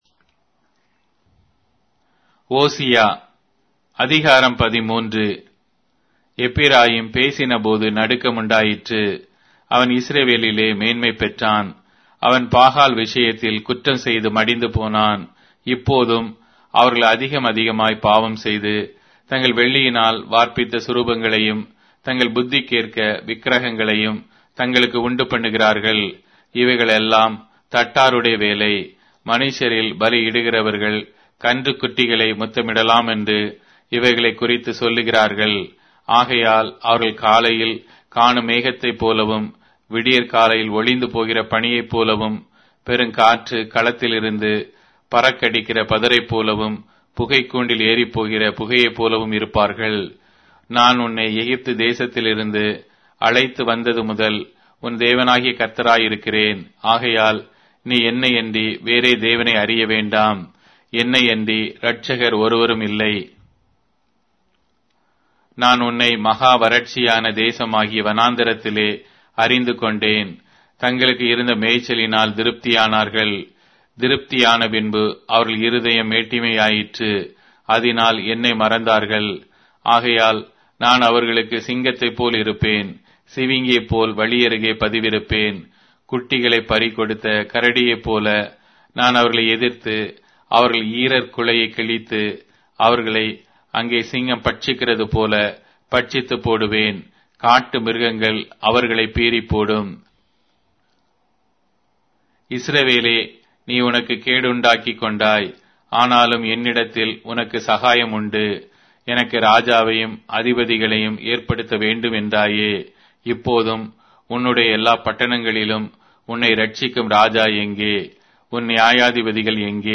Tamil Audio Bible - Hosea 10 in Bnv bible version